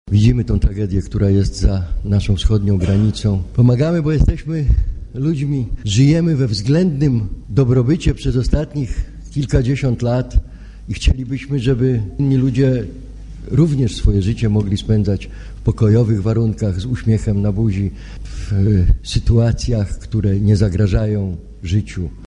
Jarosław Pakuła – mówi przewodniczący rady miasta Jarosław Pakuła.